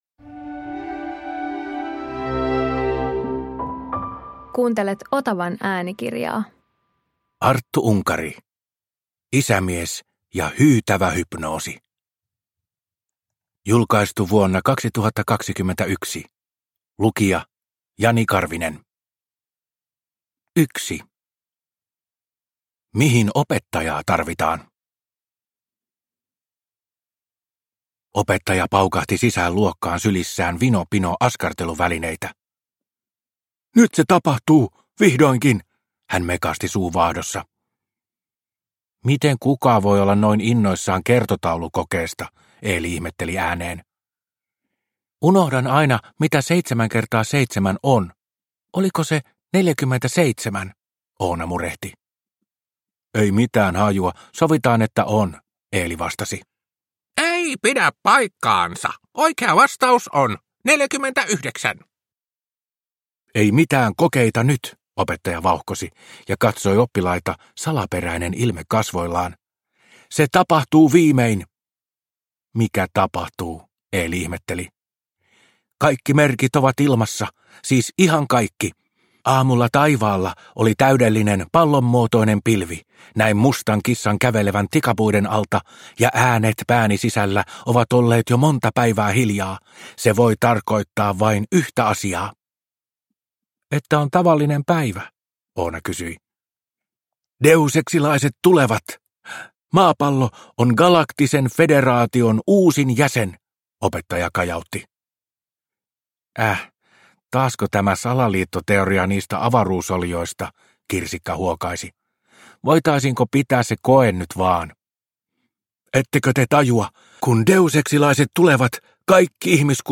Isämies ja hyytävä hypnoosi – Ljudbok – Laddas ner